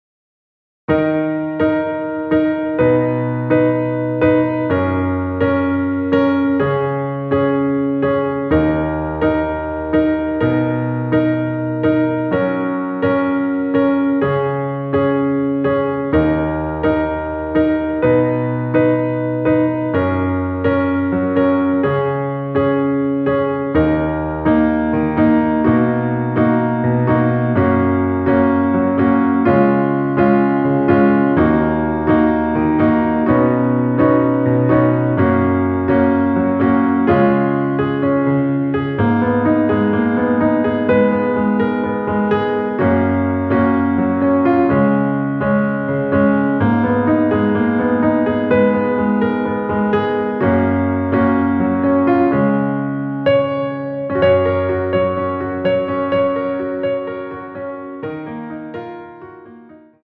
Gb
◈ 곡명 옆 (-1)은 반음 내림, (+1)은 반음 올림 입니다.
앞부분30초, 뒷부분30초씩 편집해서 올려 드리고 있습니다.
중간에 음이 끈어지고 다시 나오는 이유는